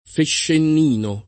[ feššenn & no ]